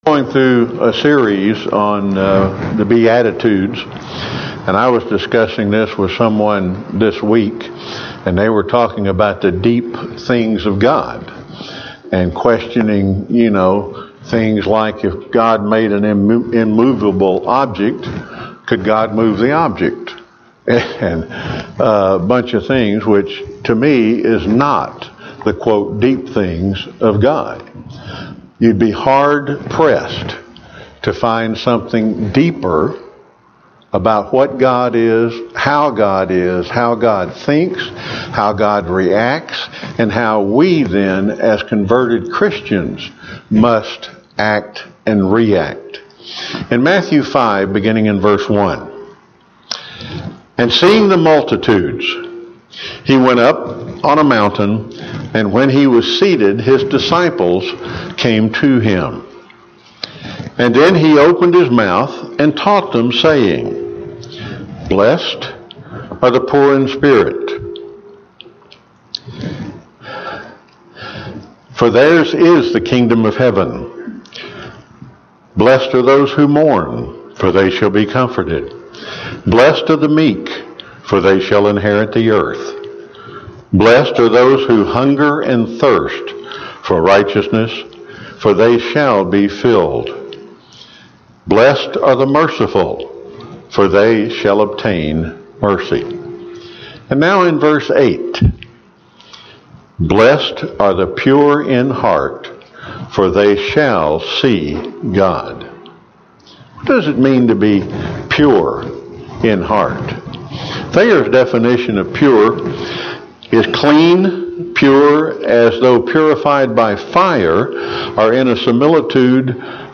Sermons
Given in Chattanooga, TN